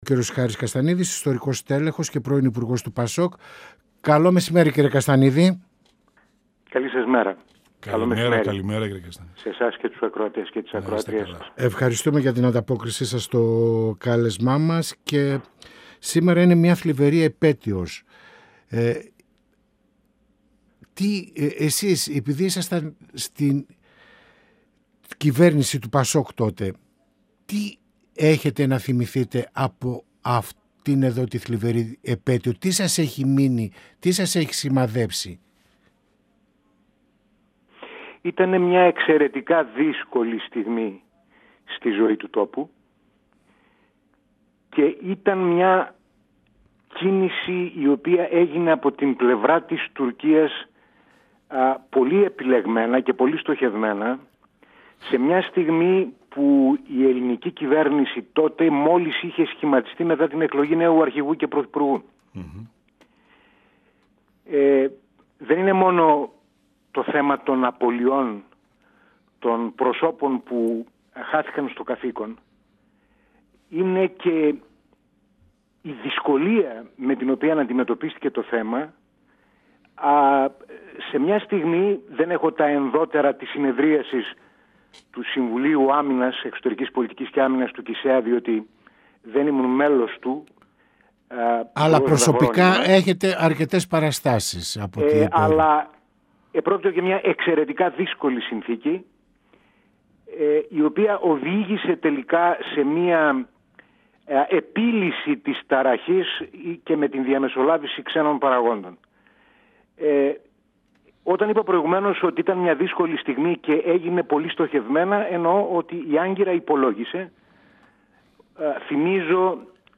Στην επέτειο των Ιμίων, στα εθνικά θέματα και στις ευθύνες του κράτους για το θάνατο των 5 εργαζομένων-γυναικών στη βιομηχανία «Βιολάντα» αναφέρθηκε ο π. Υπουργός του ΠΑΣΟΚ Χάρης Καστανίδης , μιλώντας στην εκπομπή «Πανόραμα Επικαιρότητας» του 102FM της ΕΡΤ3.